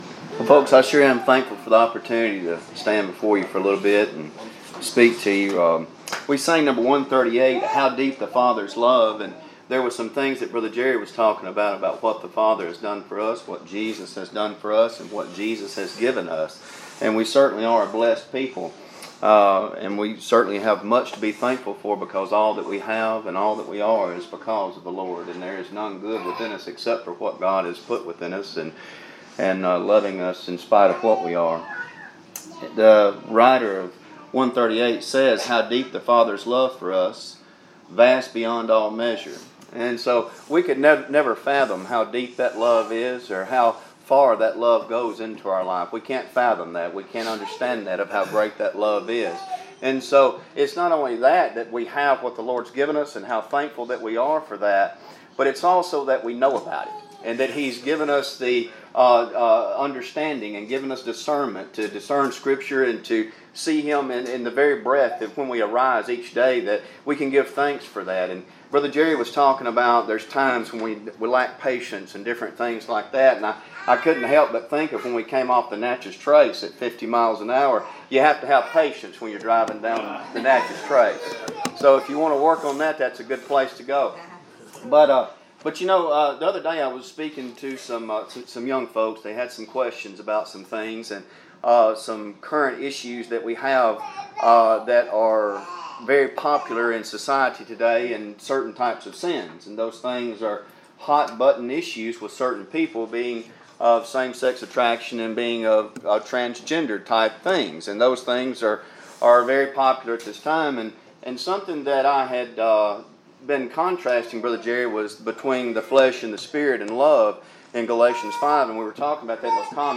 2025 Fellowship Meeting.